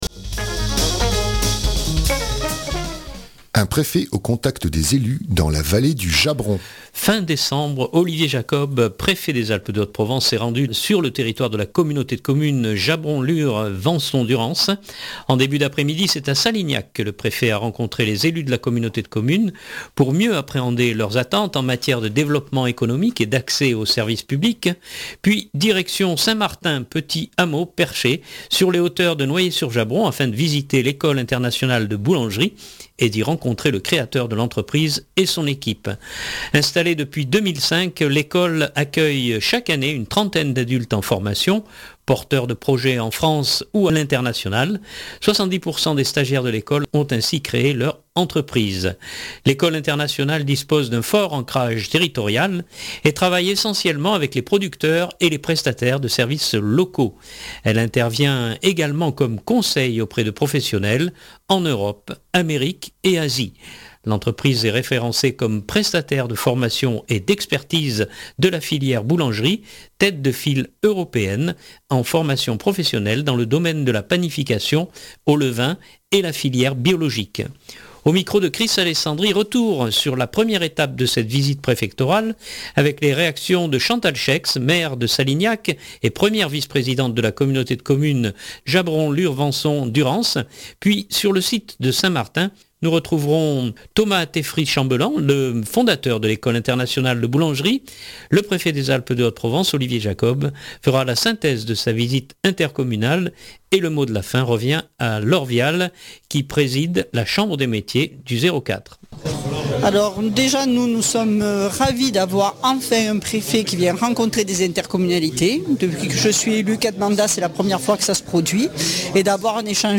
Le Préfet des Alpes de Haute Provence Olivier Jacob fera la synthèse de sa visite intercommunale